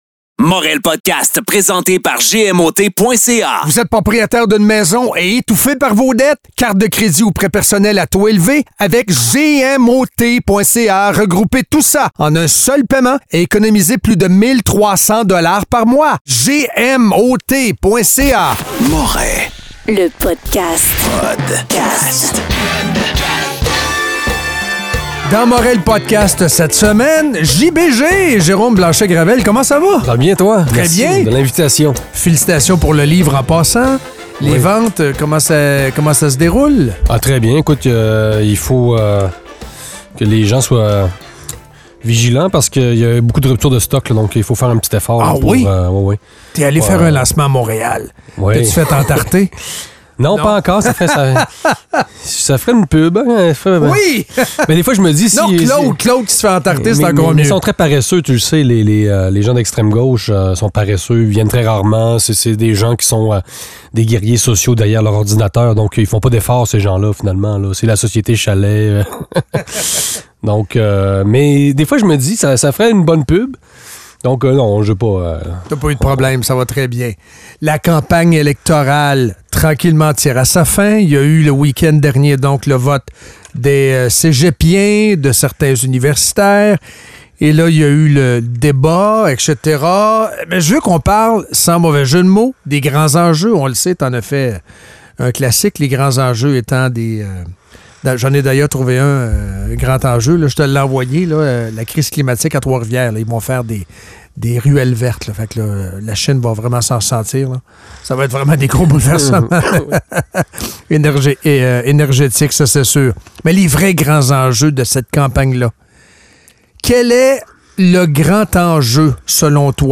Un entretien qui dérange.